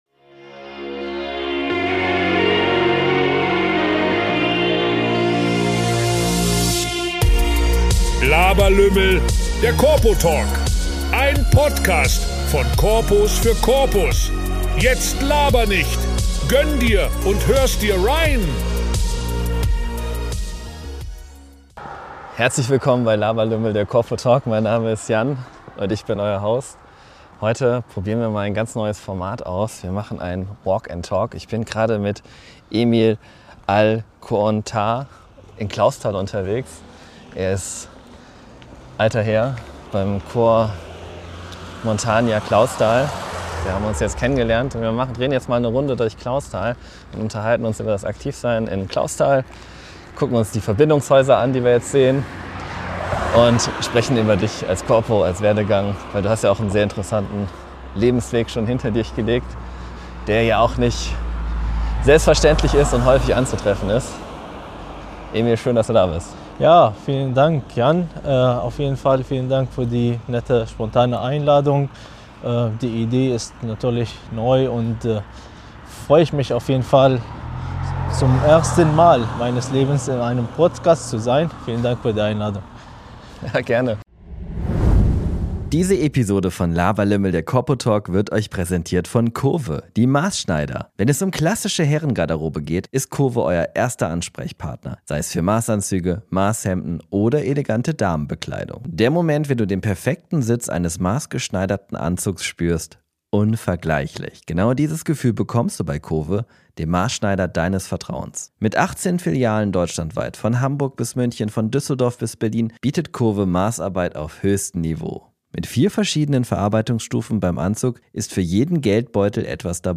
Walk & Talk in Clausthal ~ Laberlümmel - Der Korpo Talk Podcast